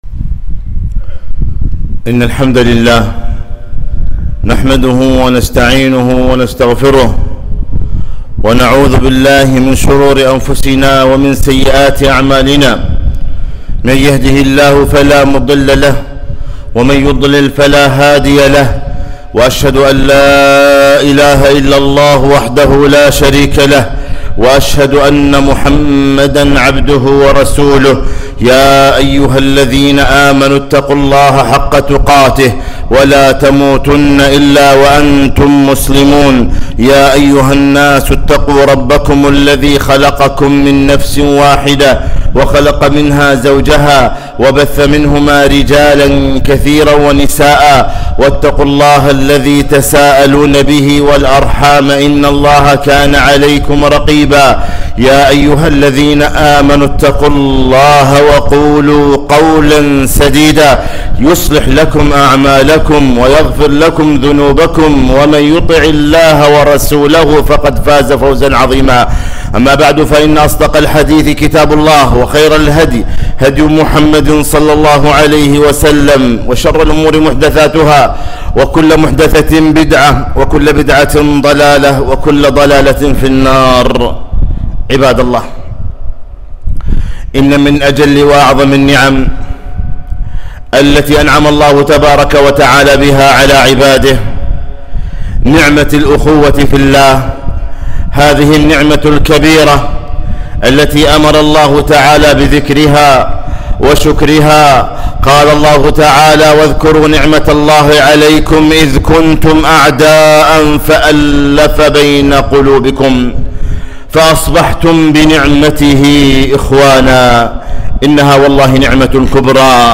خطبة - إنما المؤمنون إخوة